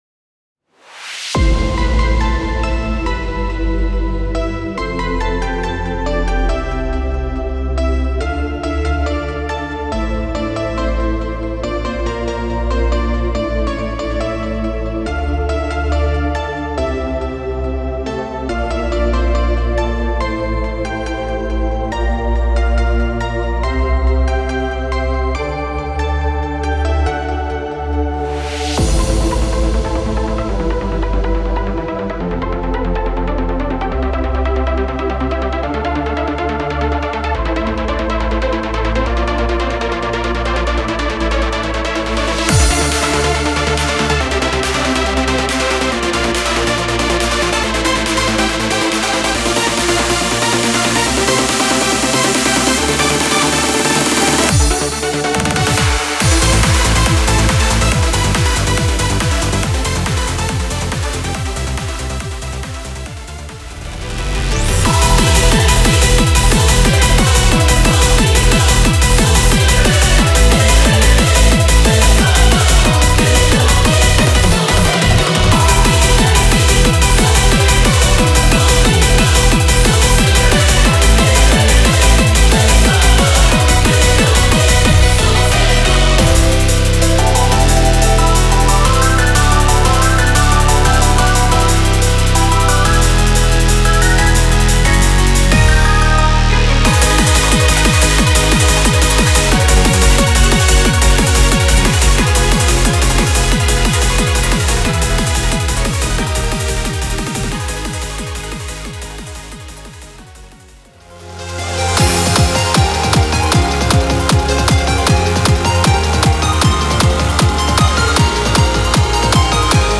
クラブの雰囲気をそのままにコンパイルした、最強のインストアレンジ全7曲。
闇夜に鳴り響く、重低音の鼓動に酔いしれよ―。